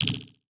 Message Bulletin Echo 2.wav